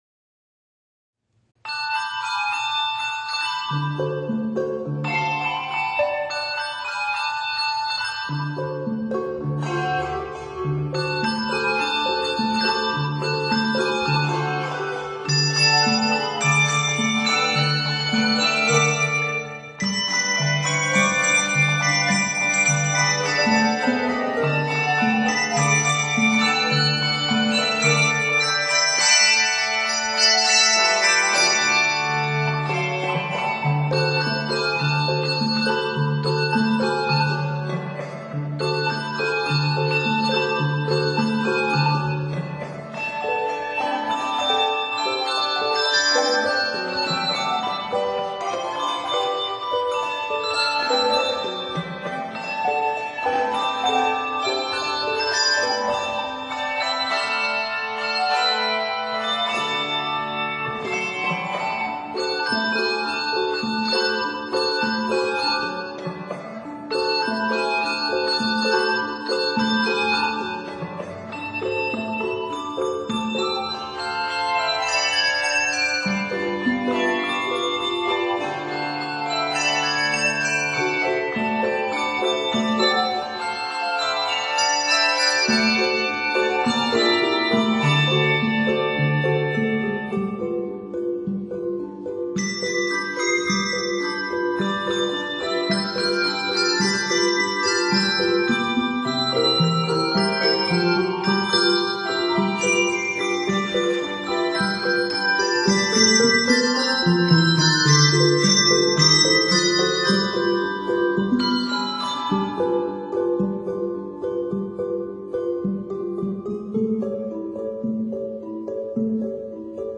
Octaves: 5